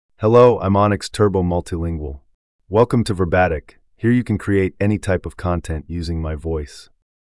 MaleEnglish (United States)
Onyx Turbo Multilingual is a male AI voice for English (United States).
Voice sample
Listen to Onyx Turbo Multilingual's male English voice.